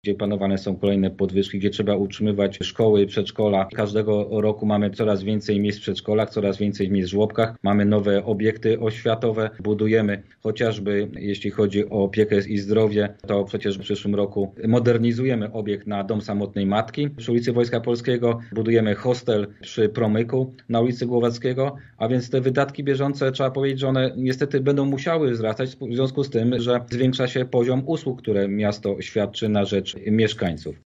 Dokonaliśmy możliwych cięć w budżecie, ale trudno sobie wyobrazić, żeby dotyczyły np. oświaty – mówi wiceprezydent miasta.
W Rozmowie po 9 Krzysztof Kaliszuk wskazywał na rosnącą liczbę zadań w tej dziedzinie: